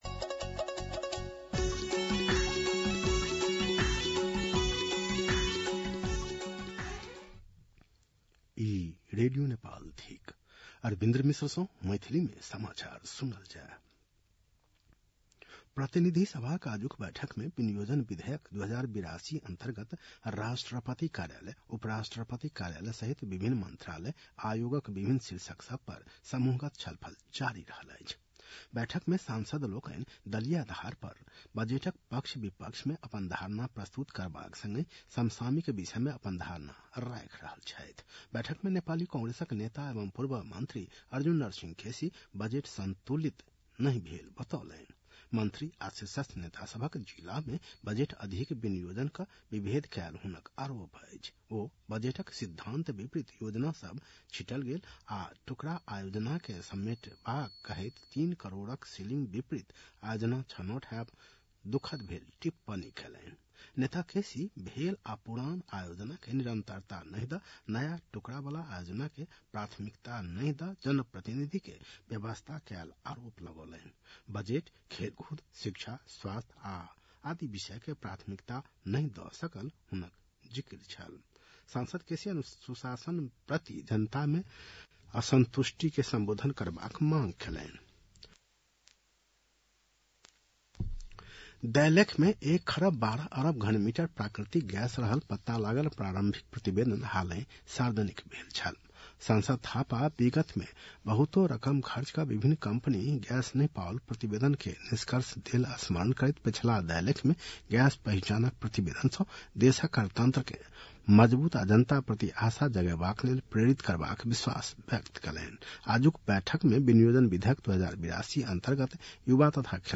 मैथिली भाषामा समाचार : ८ असार , २०८२
6.-pm-maithali-news-1-2.mp3